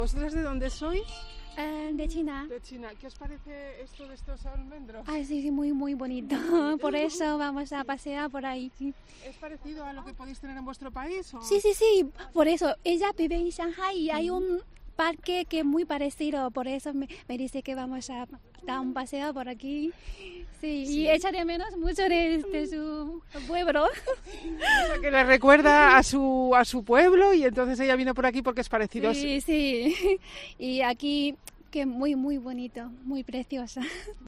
Unas visitantes chinas cuentan que el paisaje les recuerda a su país